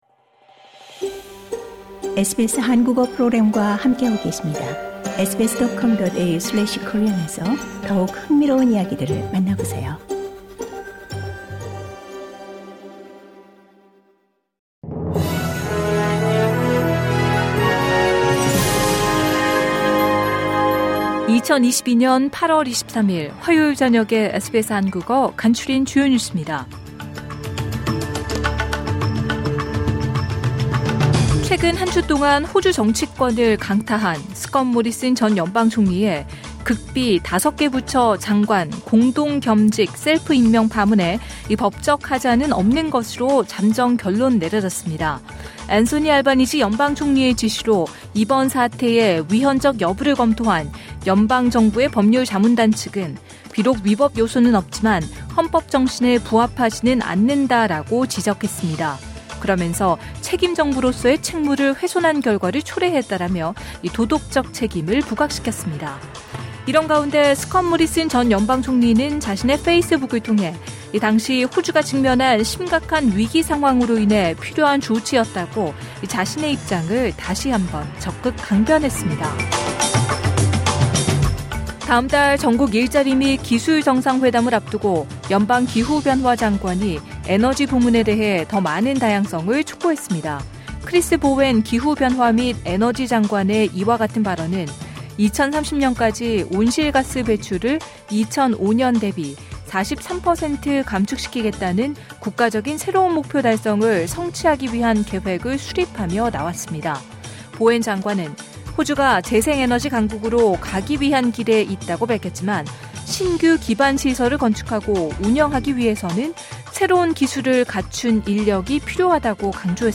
SBS 한국어 저녁 뉴스: 2022년 8월 23일 화요일
2022년 8월 23일 화요일 저녁 SBS 한국어 간추린 주요 뉴스입니다.